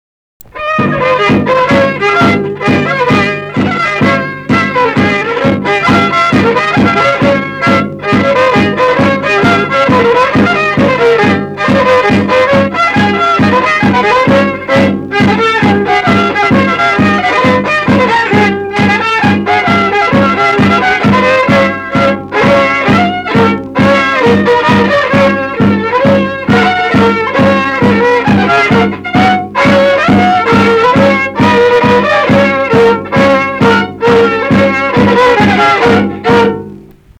šokis
Šilai (Telšiai)
vokalinis
Smuikas ir būgnas